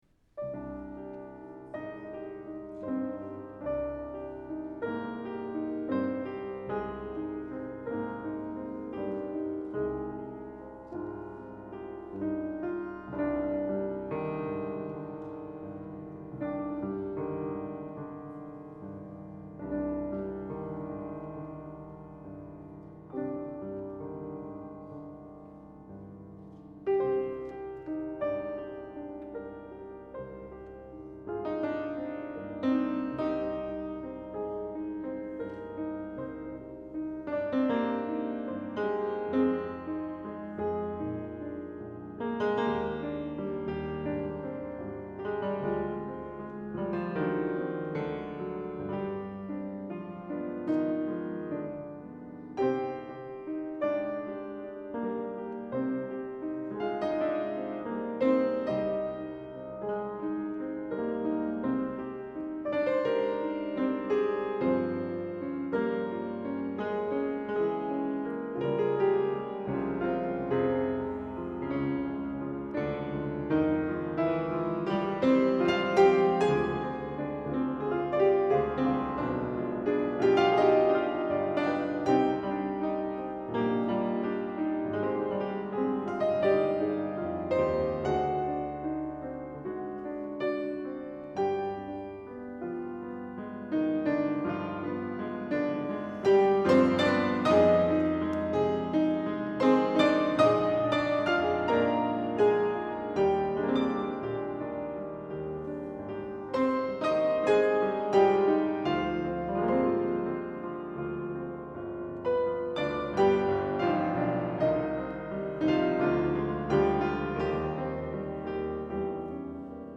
Oeuvres pour 2 pianos